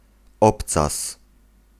Ääntäminen
IPA : /ˈhiːl/ IPA : /ˈhil/